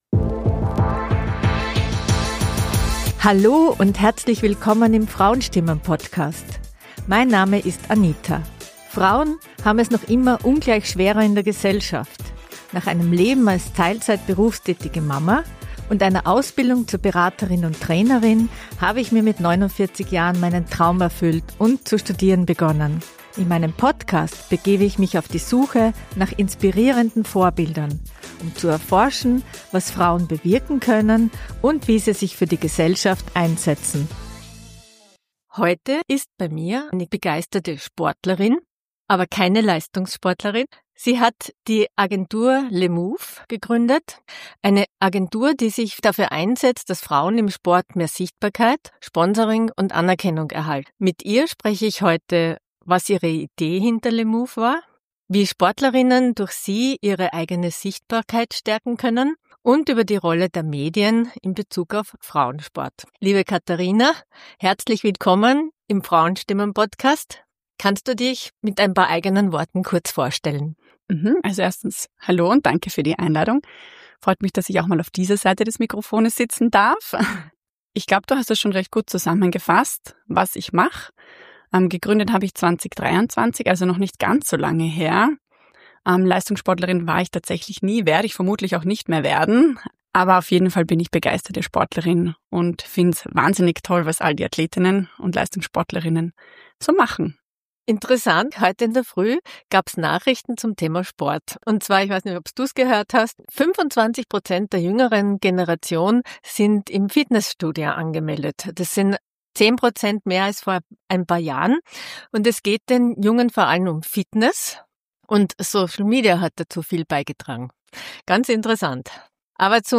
Frauenstimmen - Interviewpodcast